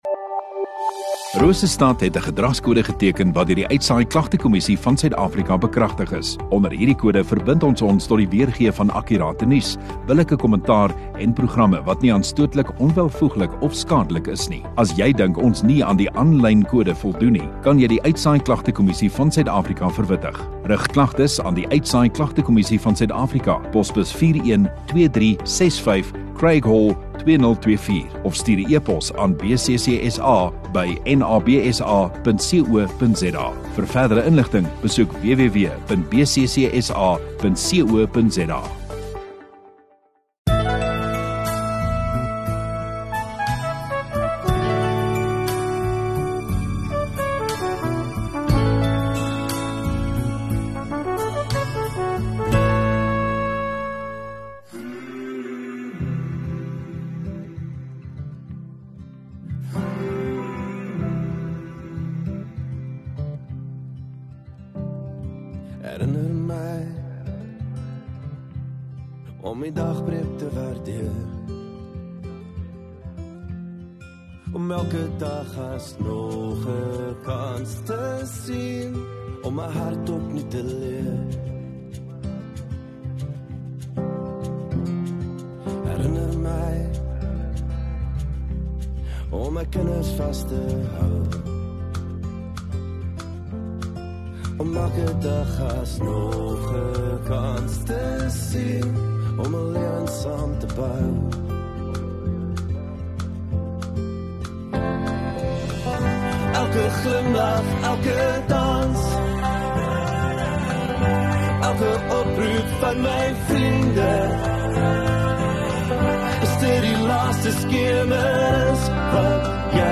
20 Jul Sondagaand Erediens